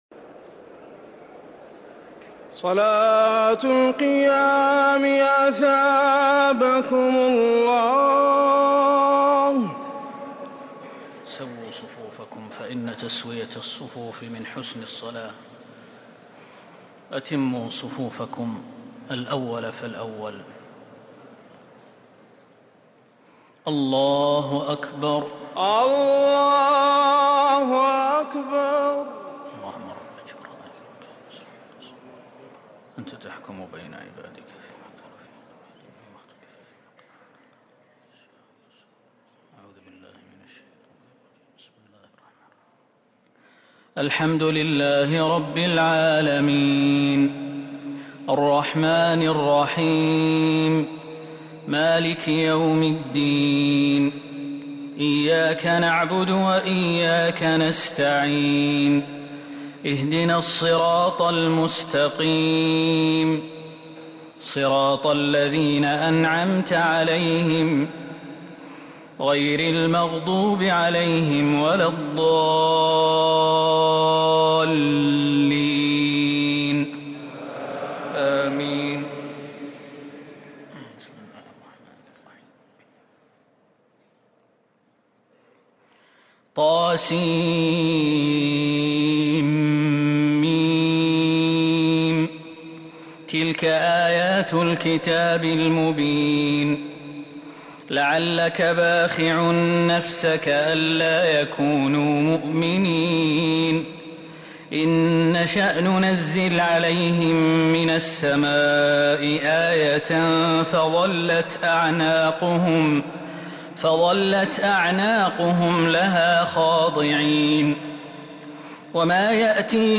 تراويح ليلة 23 رمضان 1444هـ فواتح سورة الشعراء (1-175) | Taraweeh prayer 23 St night Ramadan 1444H from surah Ash-Shuara > تراويح الحرم النبوي عام 1444 🕌 > التراويح - تلاوات الحرمين